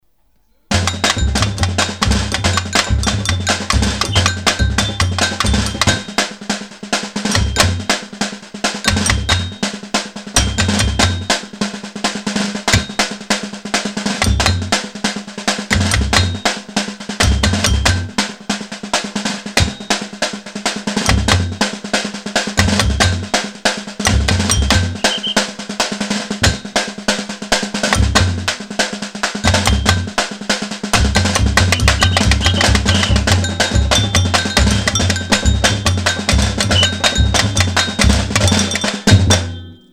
Site d'audios et de partitions sur les percussions br�siliennes jou�es dans une batucada.